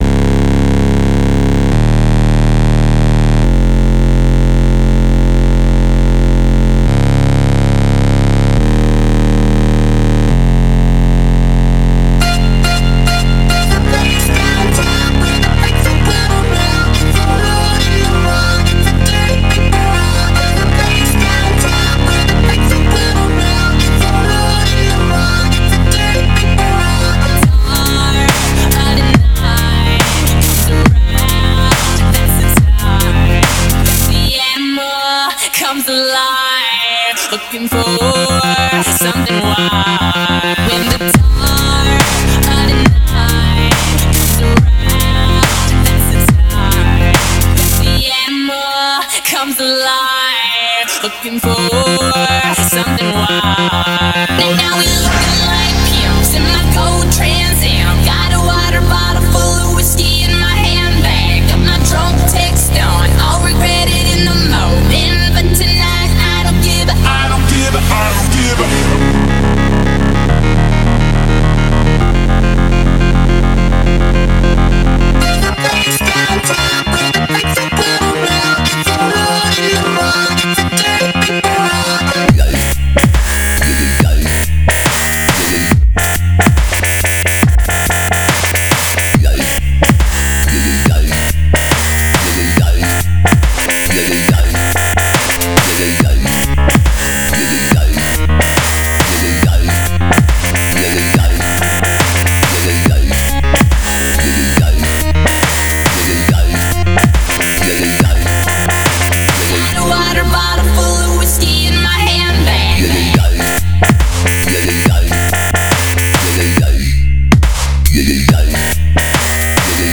Категория: Dubstep